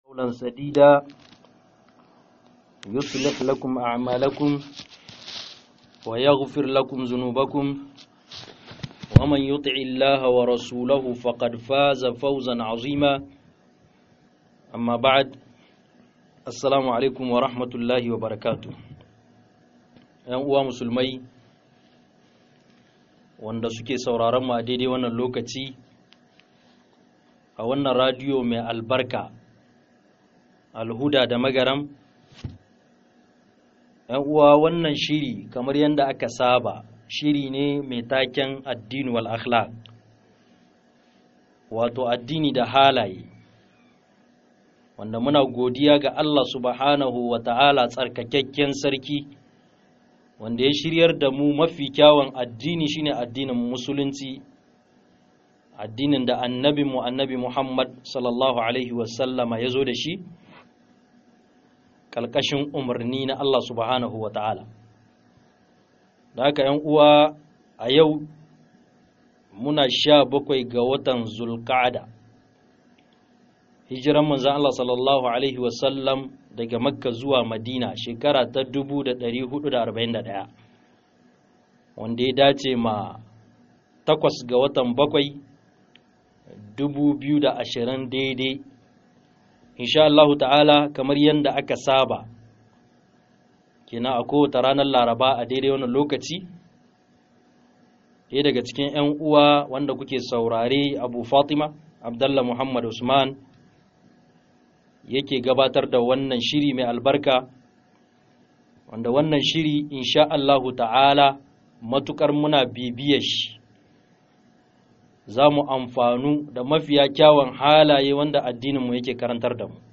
SAKIN FUSKA - MUHADARA